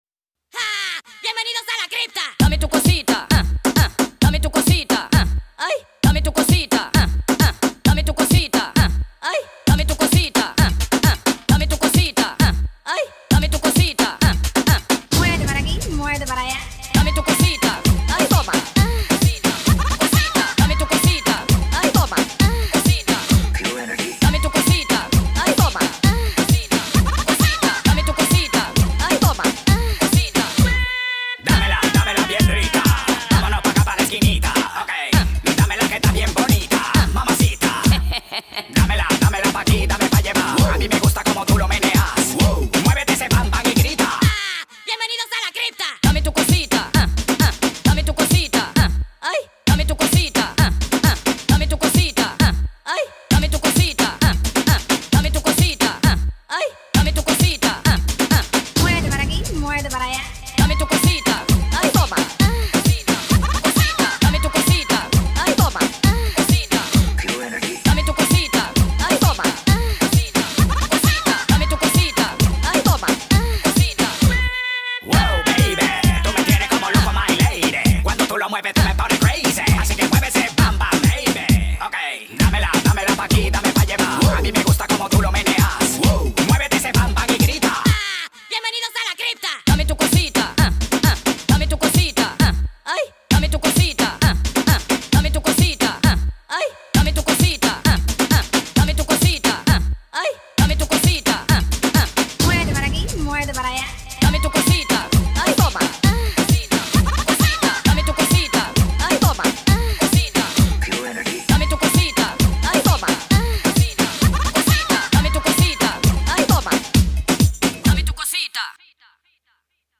با ریتمی سریع شده
شاد